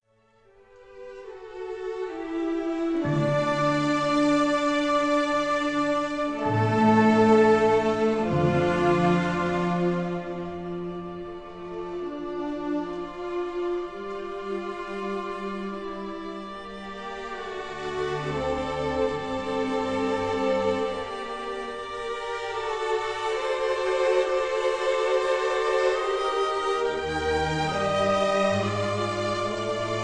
in D major